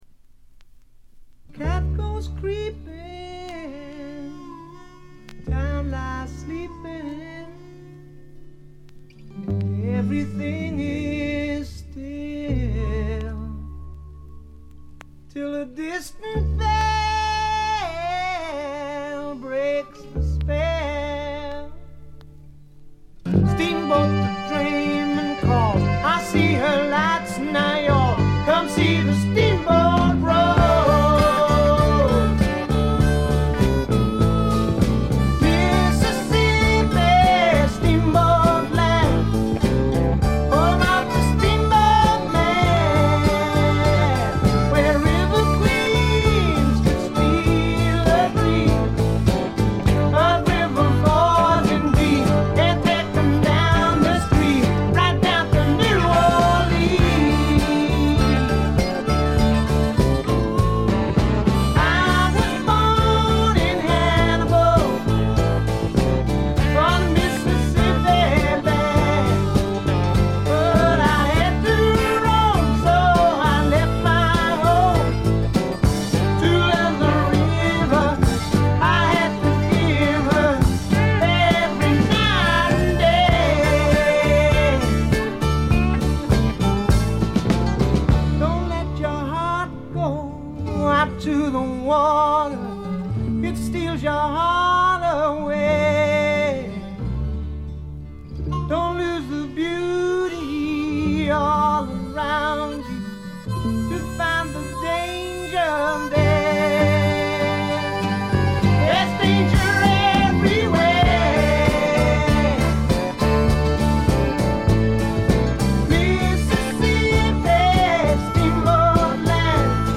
見た目に反してところどころでチリプチが出ますが大きなノイズはありません。
それに加えて激渋ポップ感覚の漂うフォークロック作品です。
この人の引きずるように伸びのあるヴォーカルは素晴らしいです。
試聴曲は現品からの取り込み音源です。